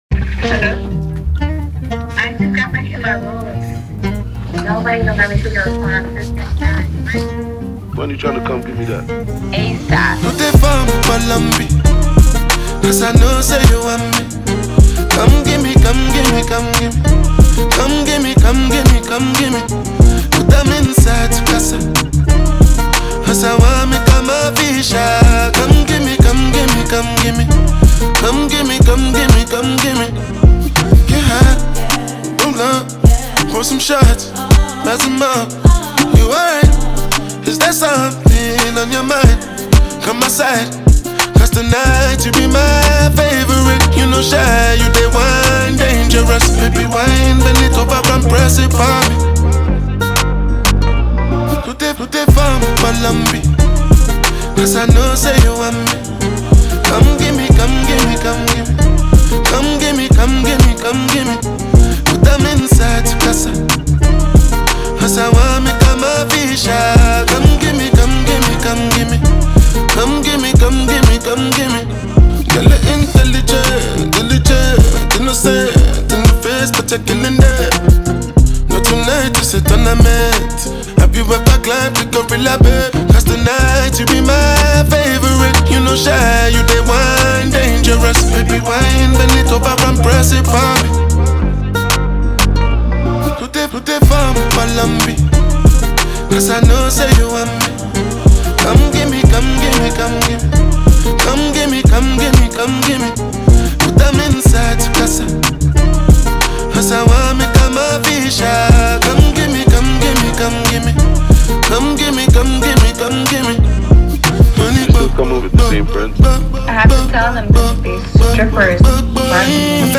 melodic offering